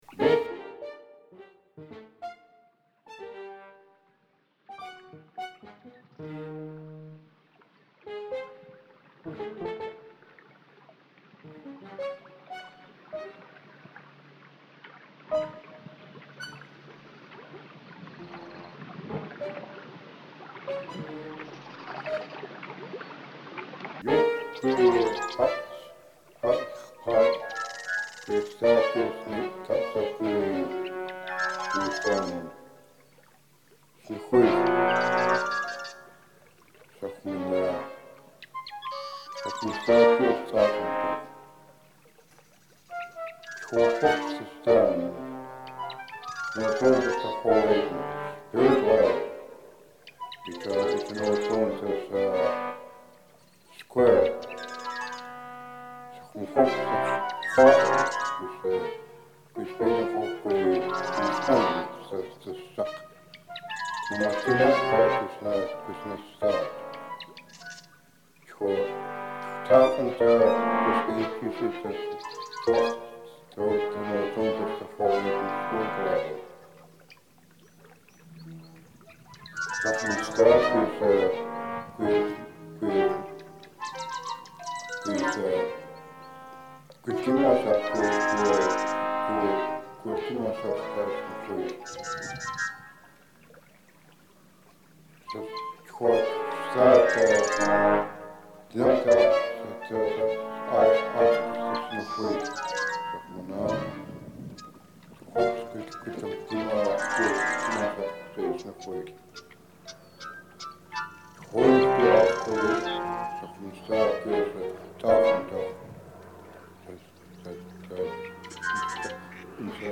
Pour Trombones, harpe, quatuor de cordes (orchestre principal),
flûtes, piccoli, xylophone & vibraphone (contrepoint des oiseaux)
Conçues à l’origine comme des tests techniques, ces transcriptions impossibles étudient le passage entre un enregistrement audio & une orchestration midi.